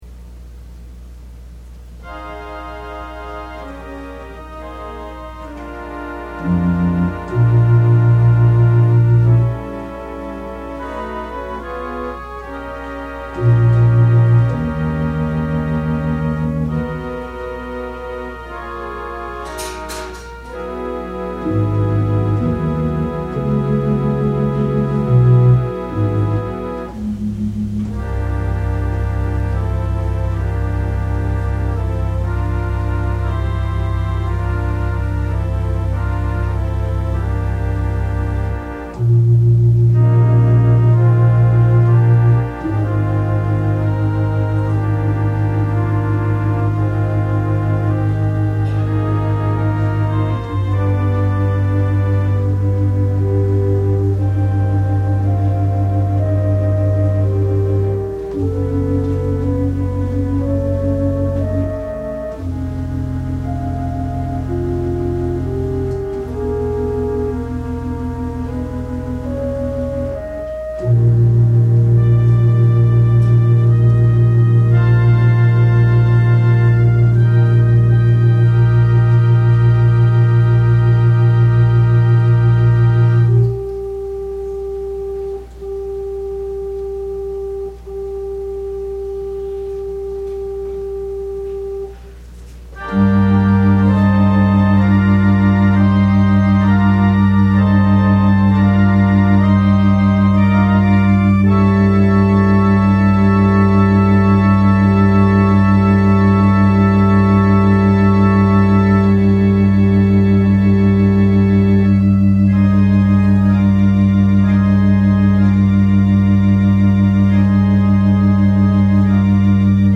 22 Music During Communion Distribution.mp3